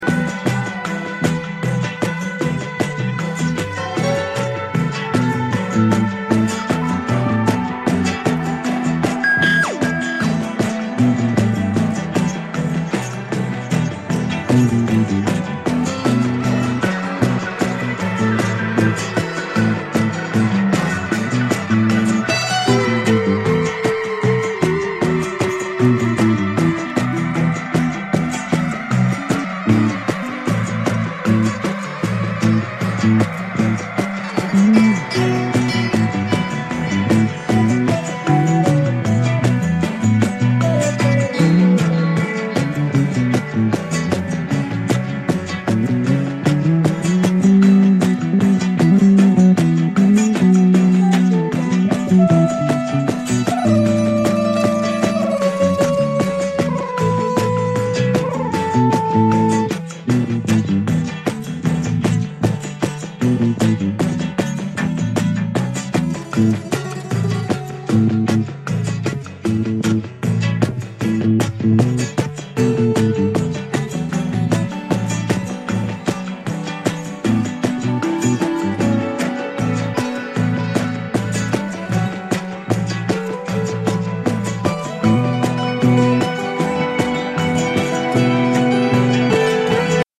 フォークを中心にダブやバレアリック的なサウンドをも展開する極上作品！